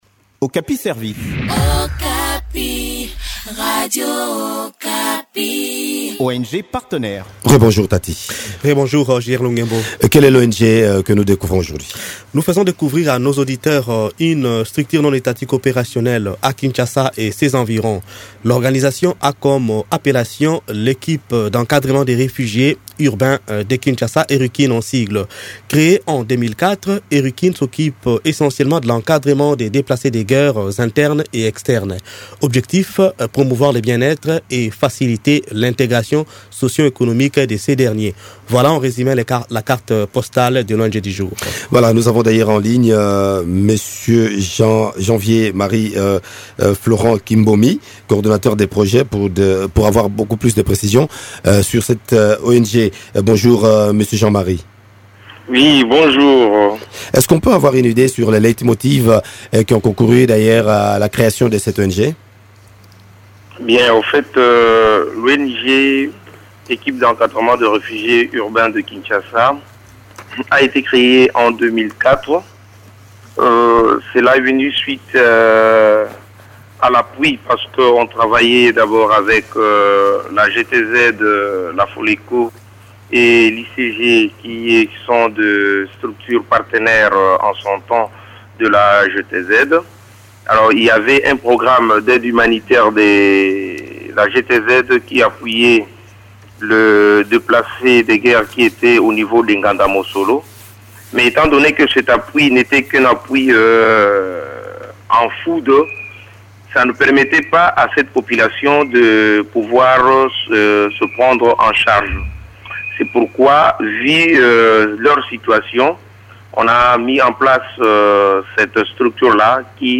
Découvrez les activités de cette structure dans cette interview